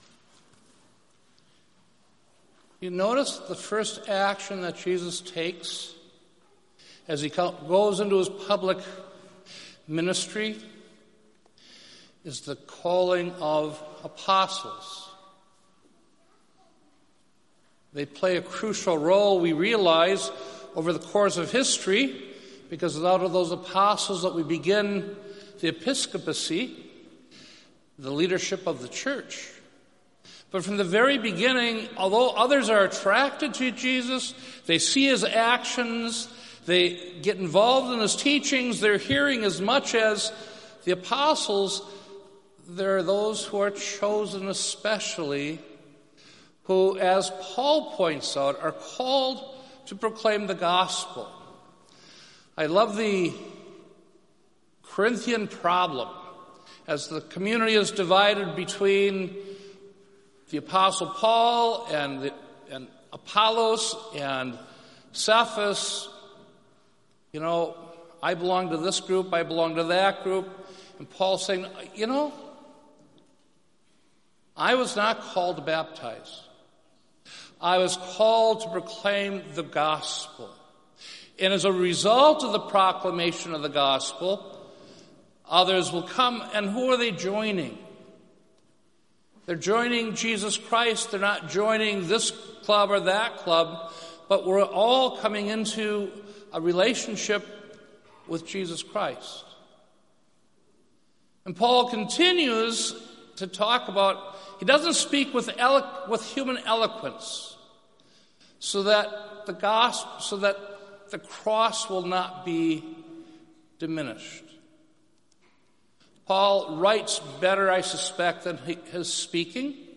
Listen to Weekend Homilies